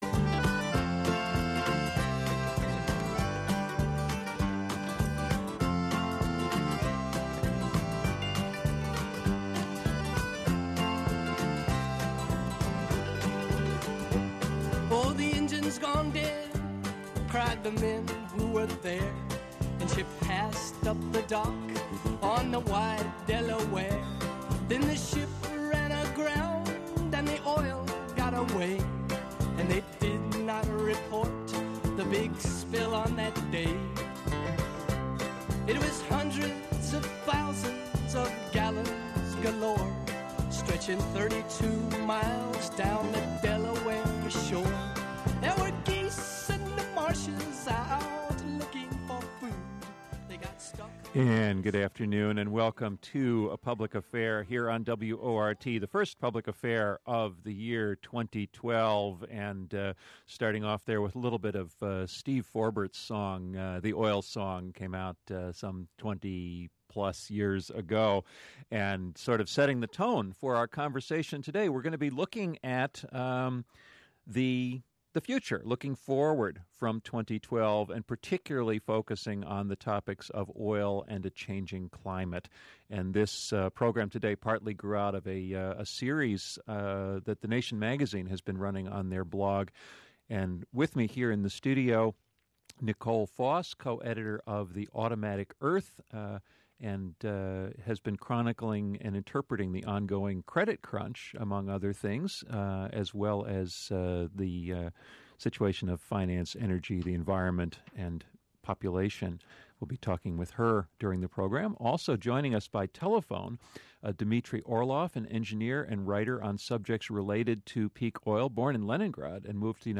This Radio WORT-FM broadcast included a “stellar” guest list including.
His sections have been removed from the replay below.